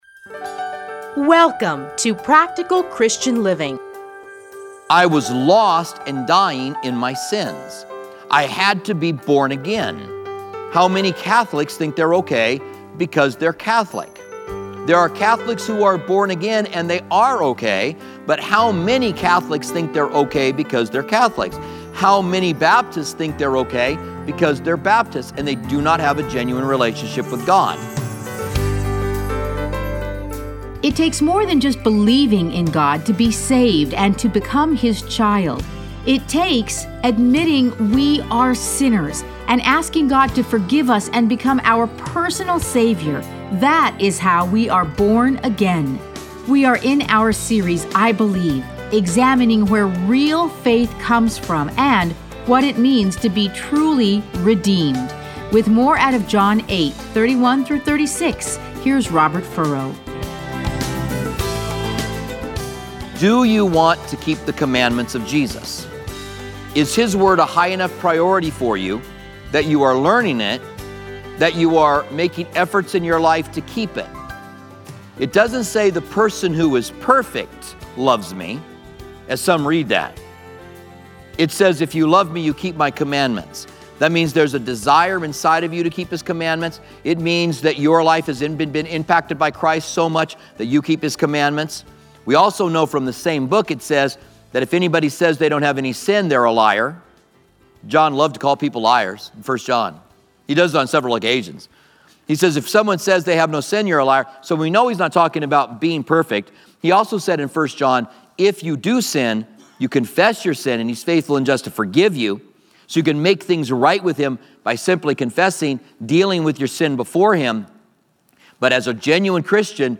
Listen to a teaching from John 8:31-36.